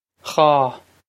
dhá ghaw
This is an approximate phonetic pronunciation of the phrase.